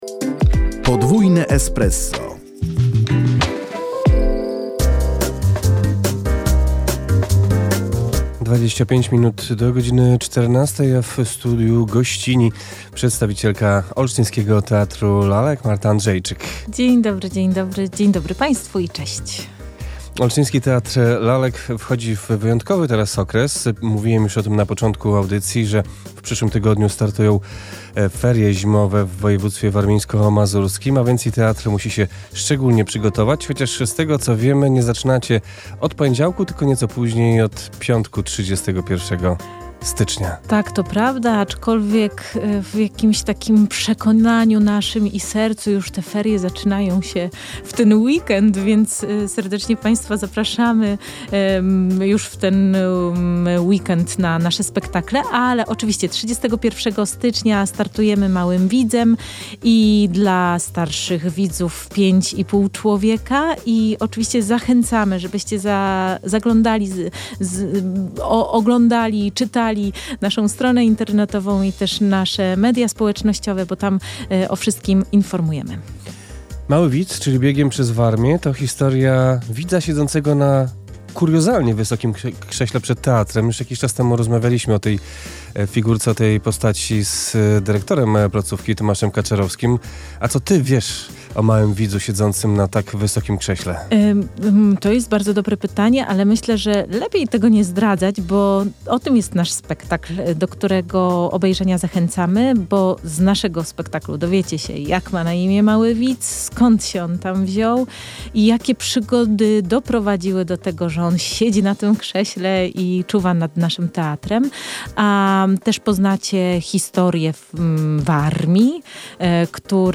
opowiedziała na naszej antenie